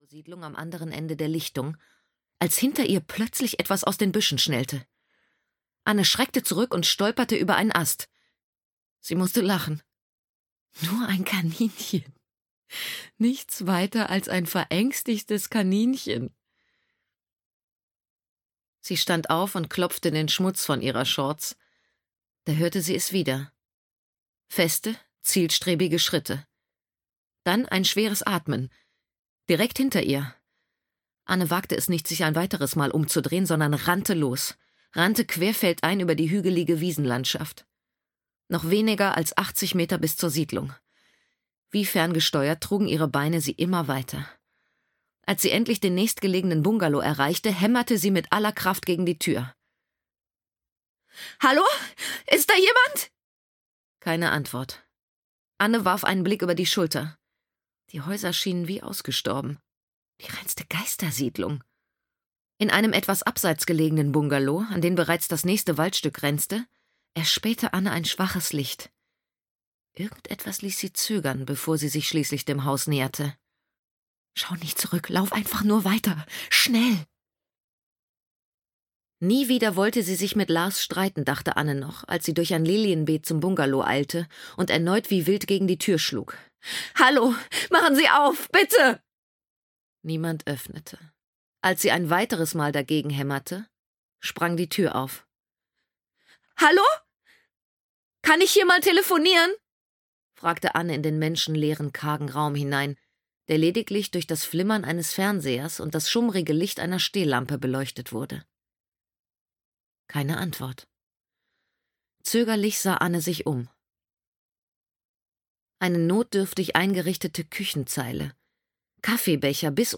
Spur der Kinder - Hanna Winter - Hörbuch